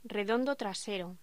Locución: Redondo trasero
voz
locución
Sonidos: Voz humana